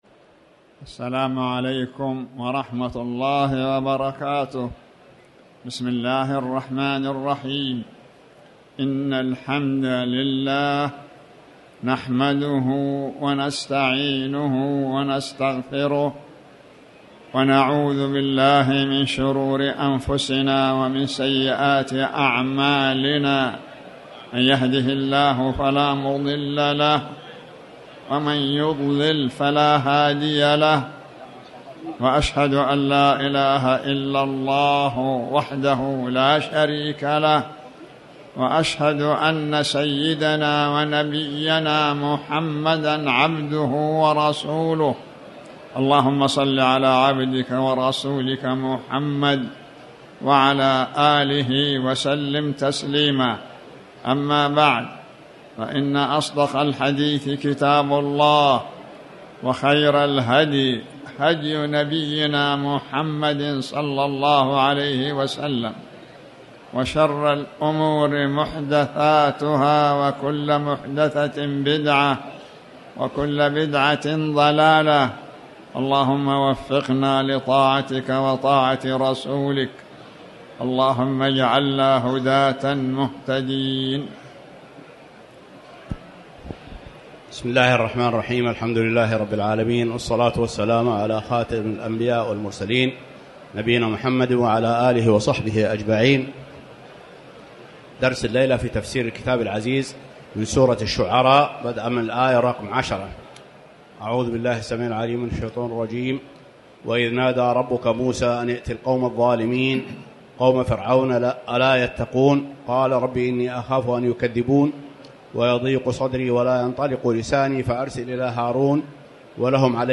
تاريخ النشر ١٢ ذو القعدة ١٤٤٠ هـ المكان: المسجد الحرام الشيخ